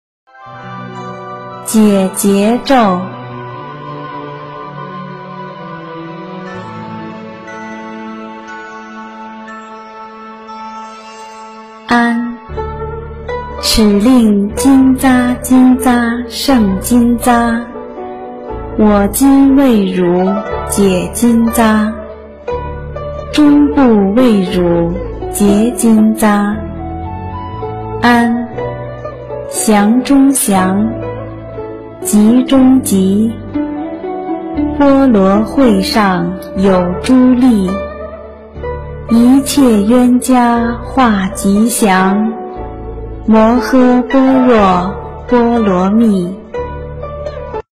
《解结咒》中文·最美大字拼音经文教念